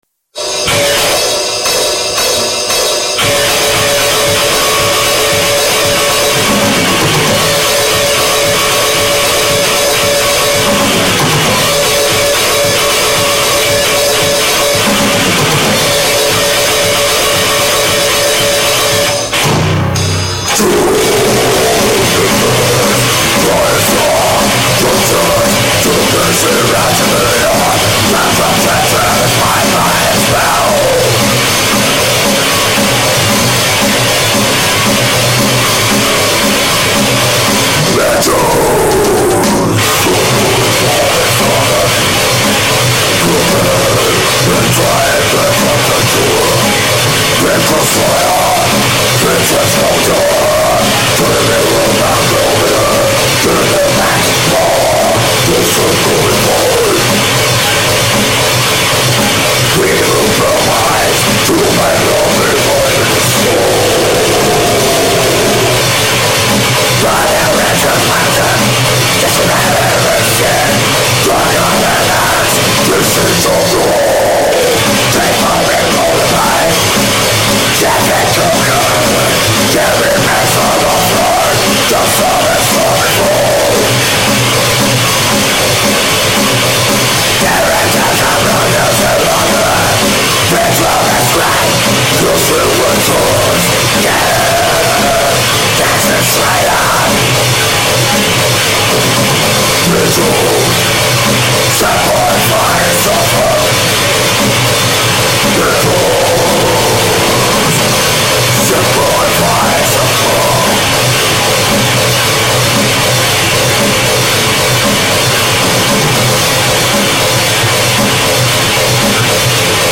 EstiloDeath Metal